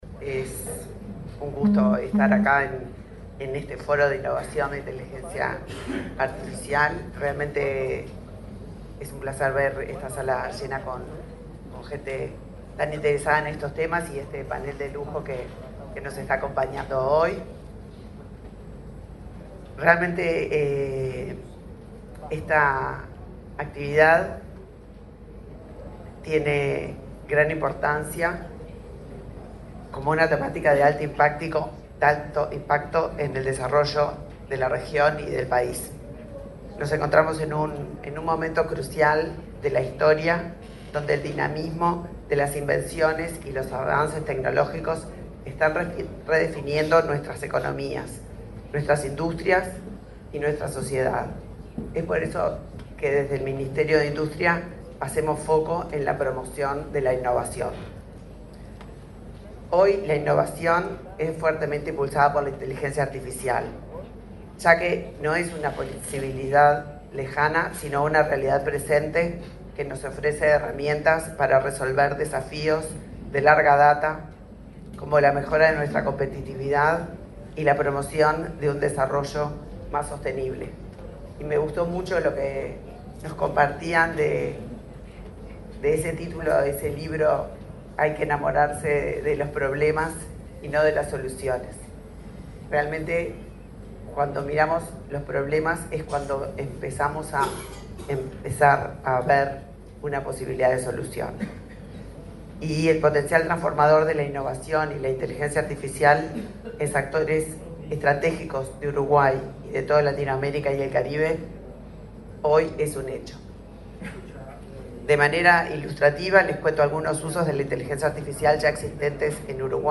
Este jueves 29, la ministra de Industria, Elisa Facio, disertó en el Foro de Innovación e Inteligencia Artificial de Uruguay: El Camino hacia un Hub
El evento se realizó en el Laboratorio Tecnológico del Uruguay.